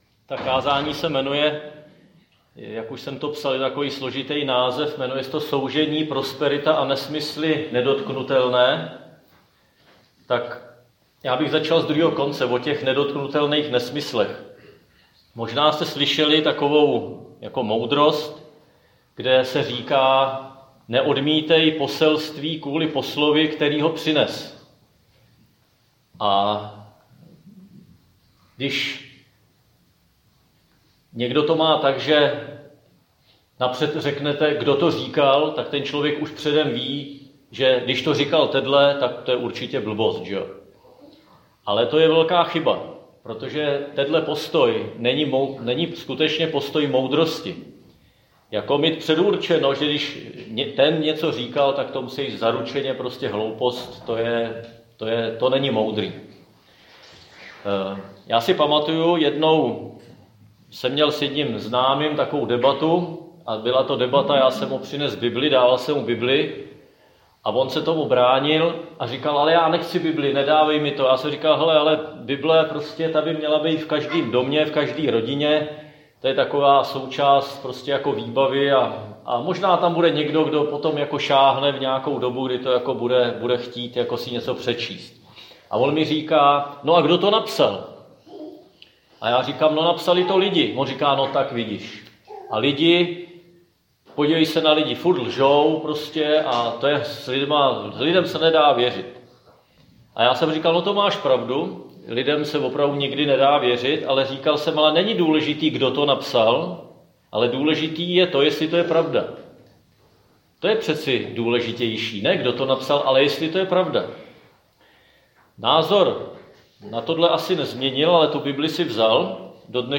Křesťanské společenství Jičín - Kázání 26.2.2023